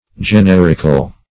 Generic \Ge*ner"ic\, Generical \Ge*ner"ic*al\, a. [L. genus,